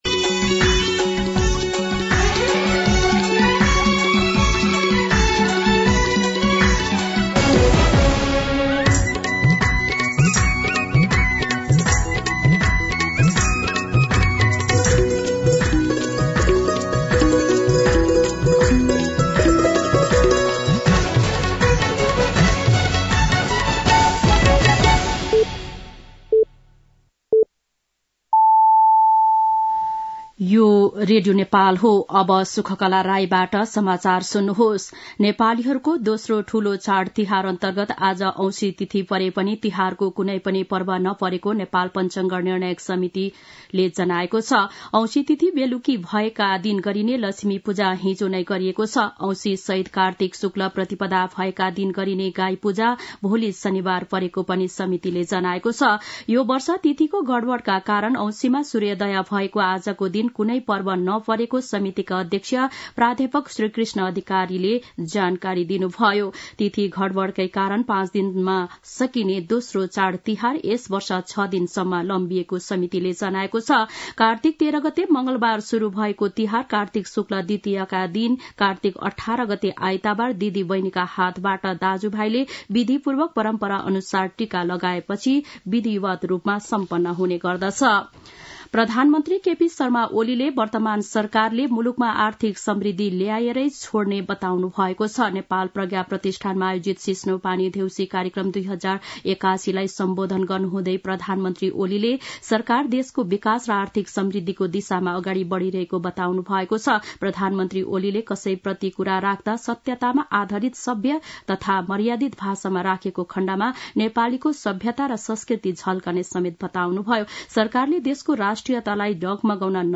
दिउँसो ४ बजेको नेपाली समाचार : १७ कार्तिक , २०८१
4-pm-Nepali-News.mp3